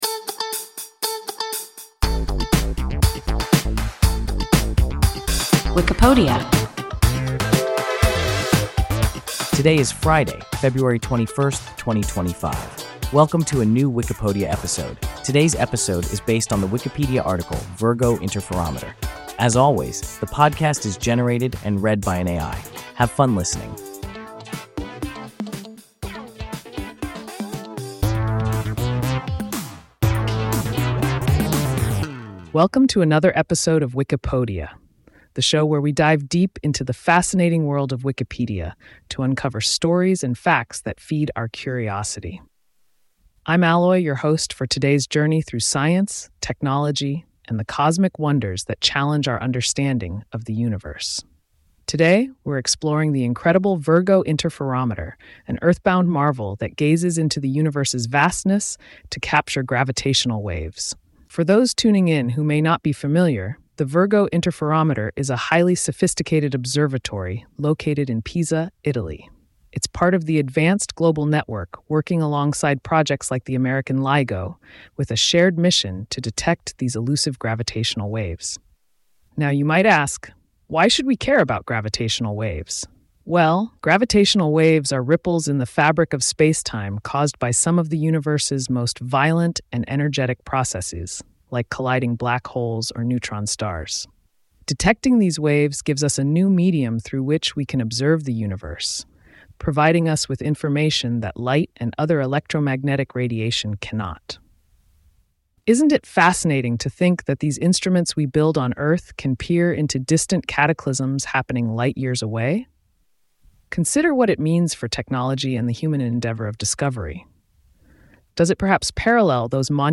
Virgo interferometer – WIKIPODIA – ein KI Podcast